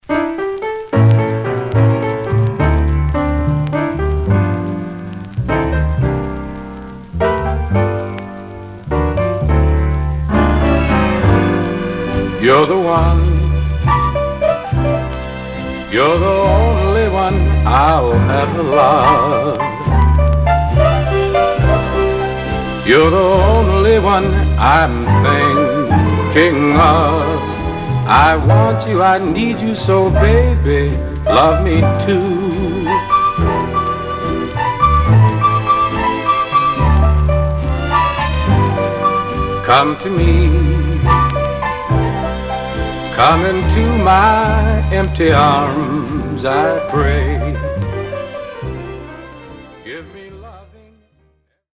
Mono
Male Black Group Condition